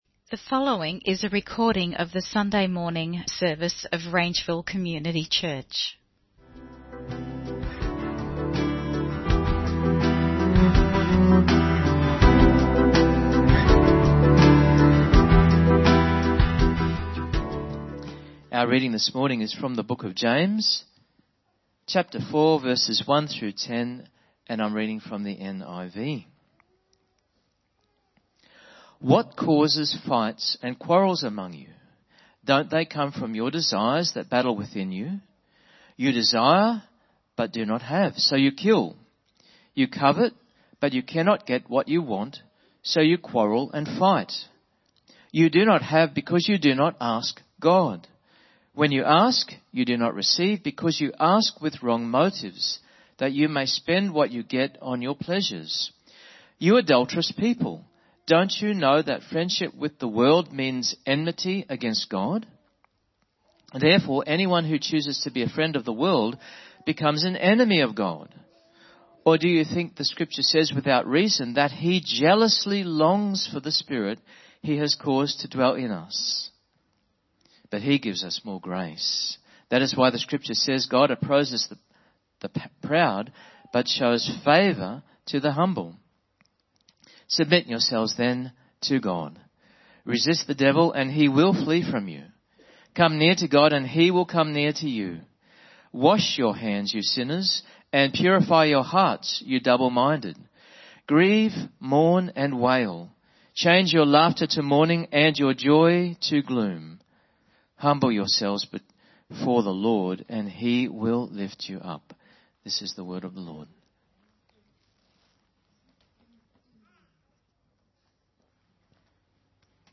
Praying for Family (Sermon Only: Video + Audio)